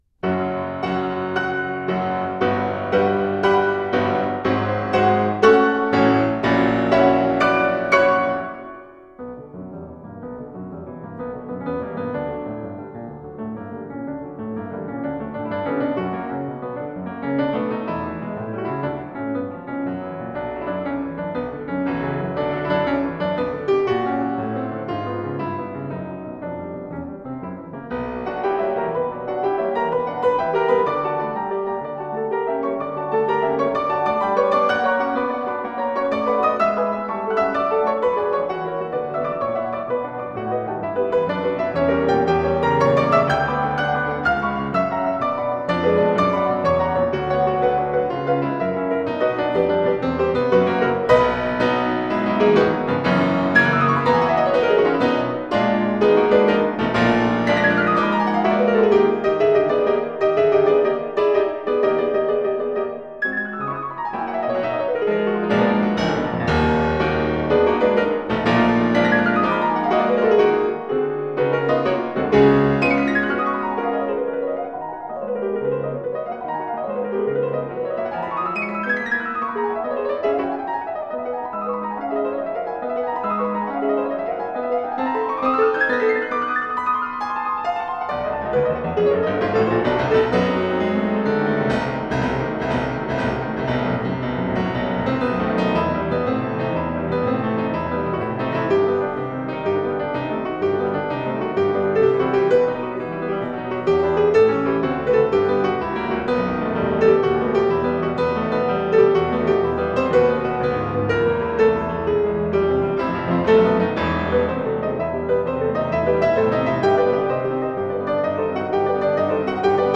Classical
Chopin, Piano Sonata No.3 in B minor, Finale, Presto non tanto. Performed by Garrick Ohlsson.